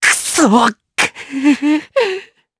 Neraxis-Vox_Sad_jp_b.wav